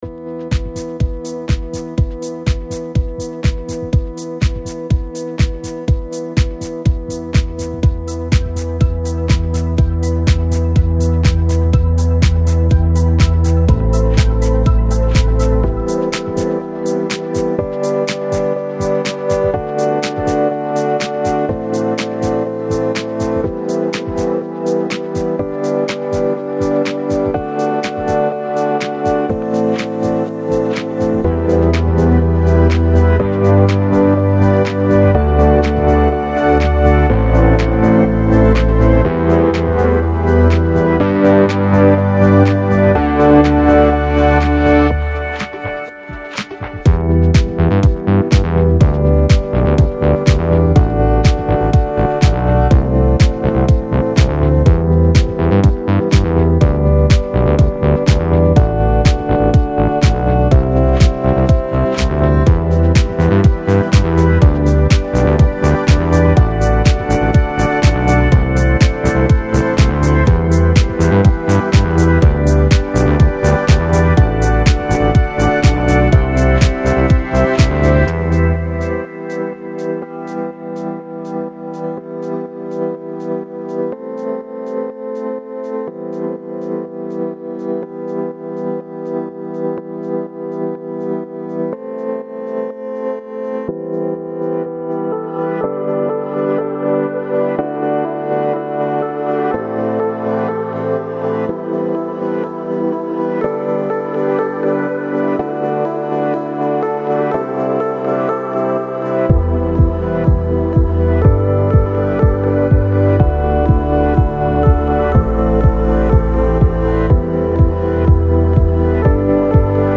Жанр: Chill Out, Downtempo, Chill House, Deep House